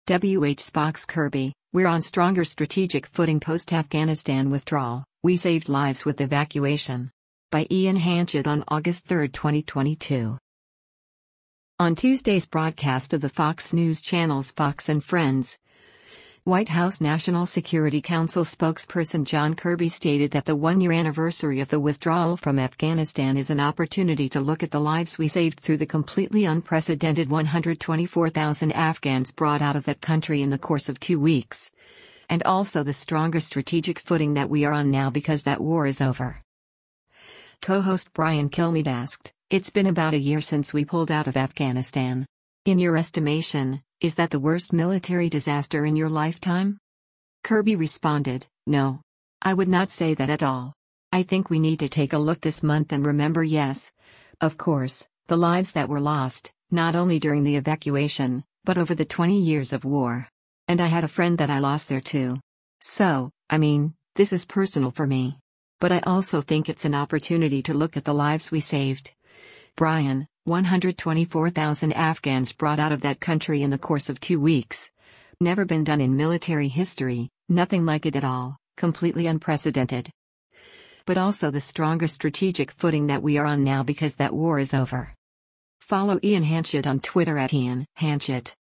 On Tuesday’s broadcast of the Fox News Channel’s “Fox & Friends,” White House National Security Council Spokesperson John Kirby stated that the one-year anniversary of the withdrawal from Afghanistan is “an opportunity to look at the lives we saved” through the “completely unprecedented” “124,000 Afghans brought out of that country in the course of two weeks,” and also “the stronger strategic footing that we are on now because that war is over.”
Co-host Brian Kilmeade asked, “It’s been about a year since we pulled out of Afghanistan. In your estimation, is that the worst military disaster in your lifetime?”